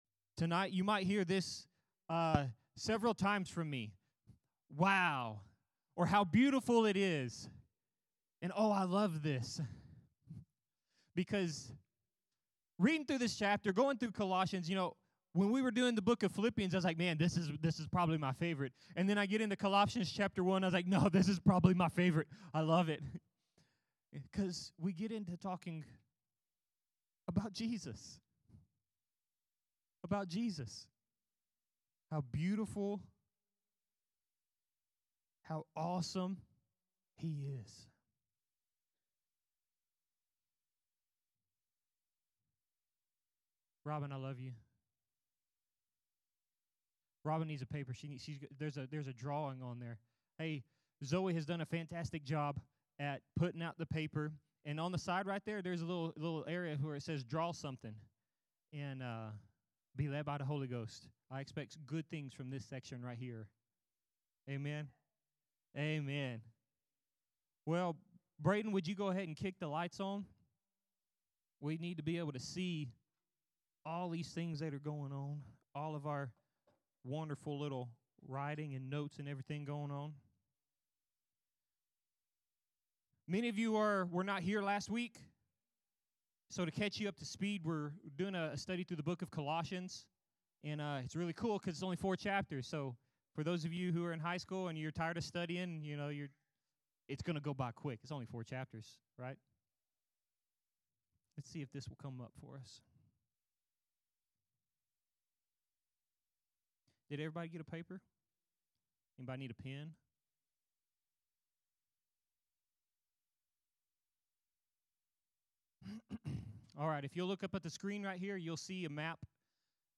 Sermons | Harvest Time Church